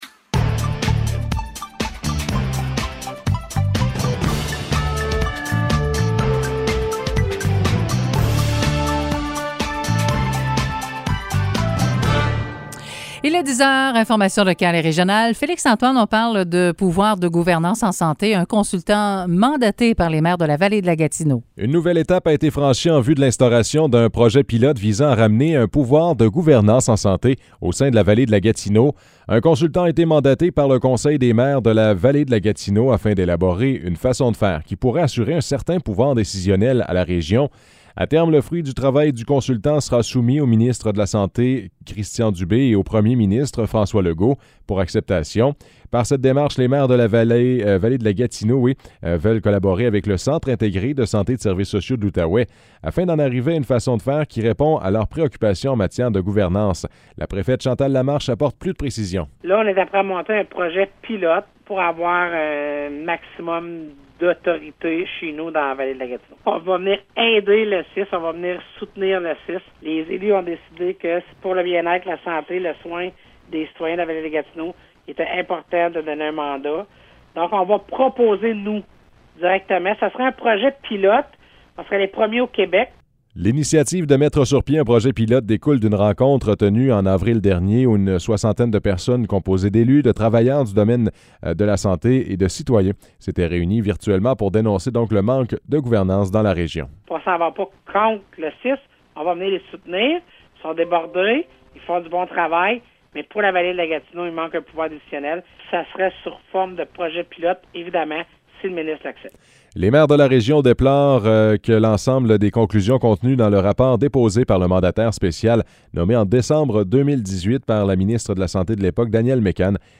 Nouvelles locales - 19 octobre 2021 - 10 h